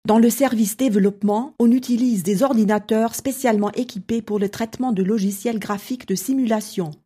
sprecherdemos
französisch w_01